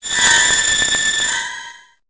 Cri de Sidérella dans Pokémon Épée et Bouclier.